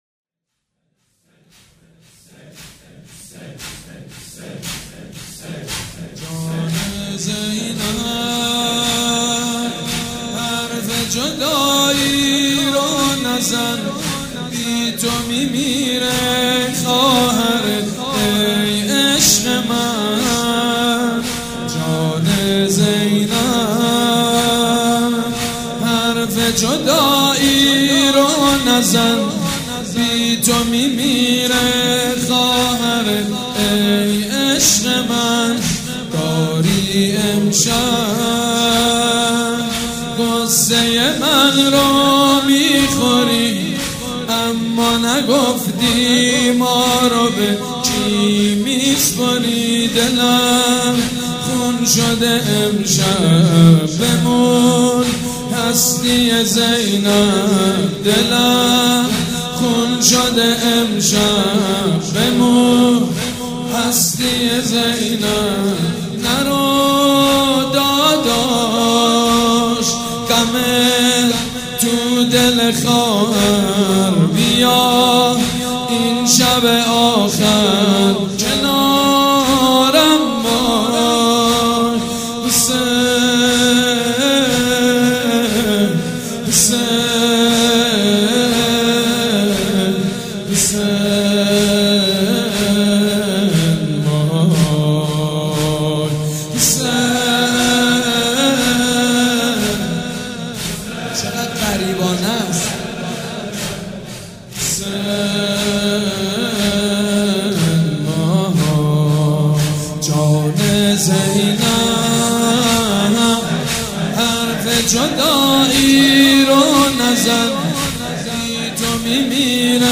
شب دهم محرم الحرام‌ سه شنبه 20 مهرماه ۱۳۹۵ هيئت ريحانة الحسين(س)
سبک اثــر زمینه
مراسم عزاداری شب عاشورا